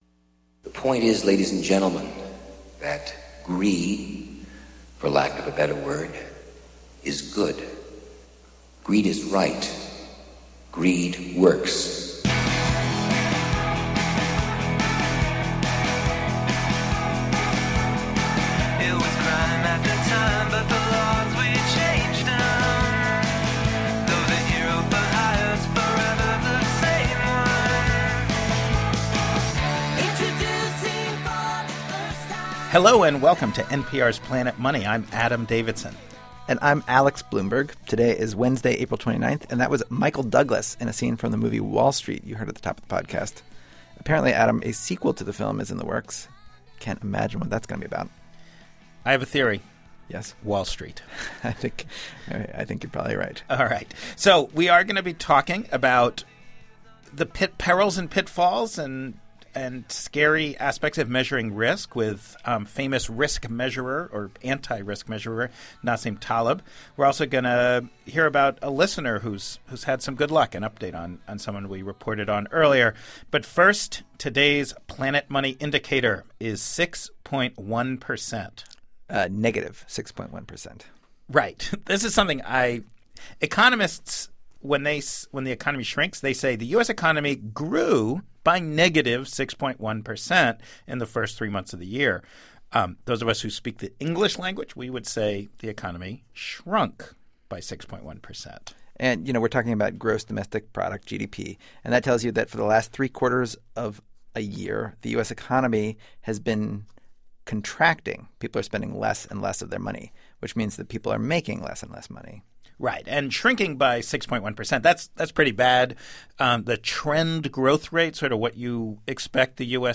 This week Nassim Taleb, the economist who lives by the Black Swan Theory, joined Ian Bremmer of Eurasia Group and The Fat Tail in our studios. Taleb's message to the world goes like this: Never mind math to manage risk. End the bond industry.